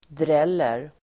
Uttal: [dr'el:er]